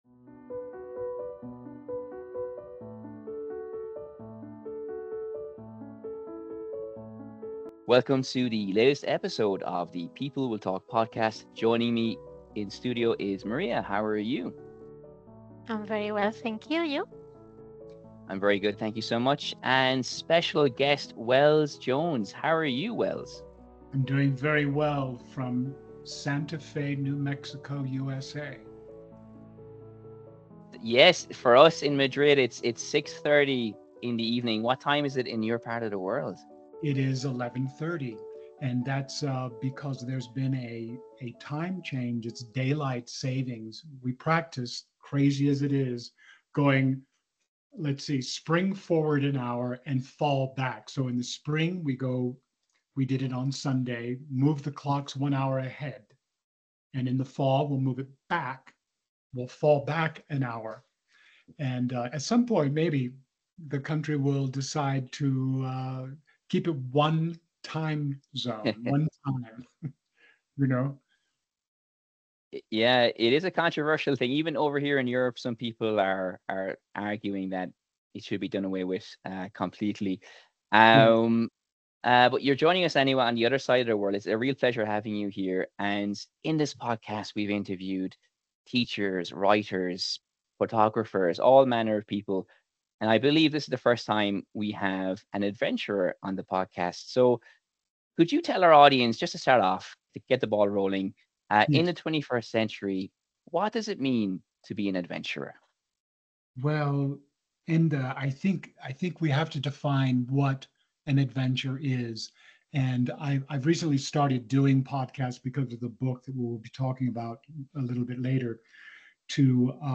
Adventurer & Writer: Interviewing